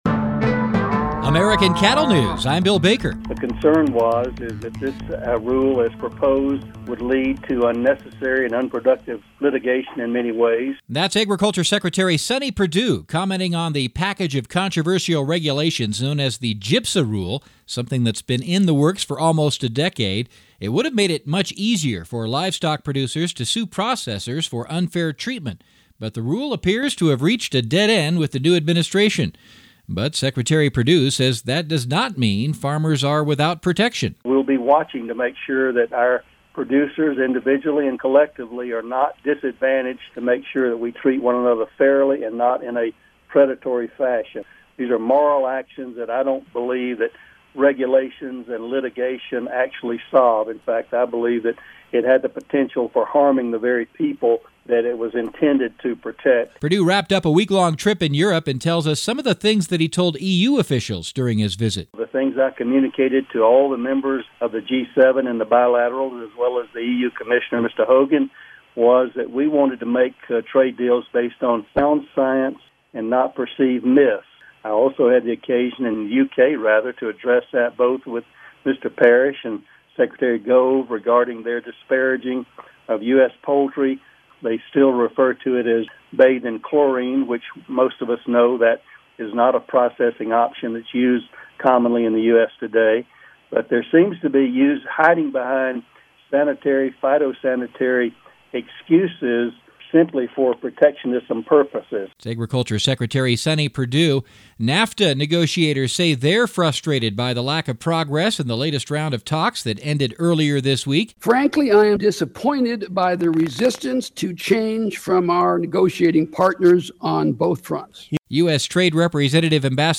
Ag Secretary Sonny Perdue confirmed the decision with reporters.